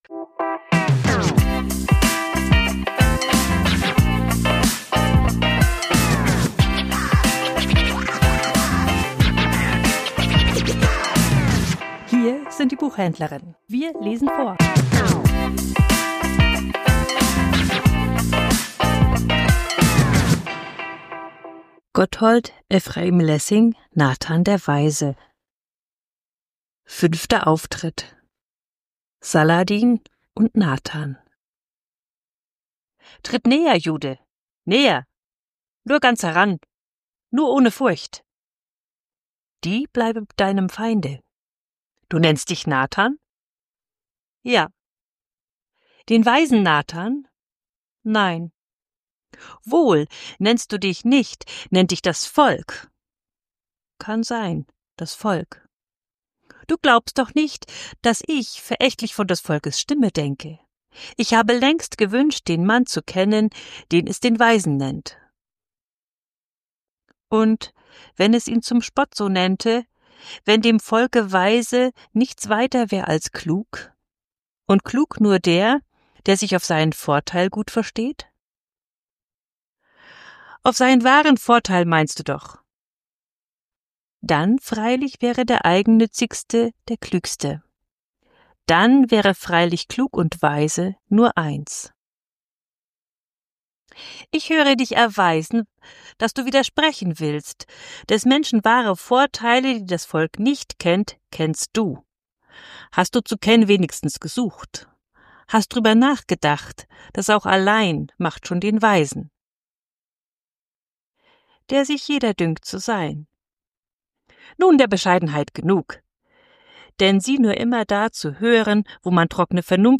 Vorgelesen: Nathan der Weise ~ Die Buchhändlerinnen Podcast